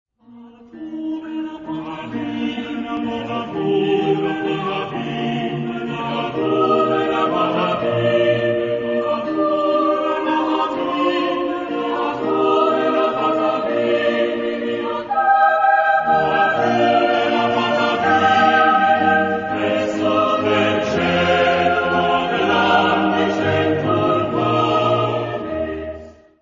Género/Estilo/Forma: Cantata ; Sagrado
Carácter de la pieza : piadoso ; ferviente
Tipo de formación coral: SSATB  (5 voces Coro mixto )
Solistas : SSATB  (5 solista(s) )
Instrumentación: Ensamble instrumental  (4 partes instrumentales)
Instrumentos: Bajo Continuo ; Violín (2) ; Violone
Tonalidad : mi bemol mayor
por Dresdner Kammerchor dirigido por Hans-Christoph Rademann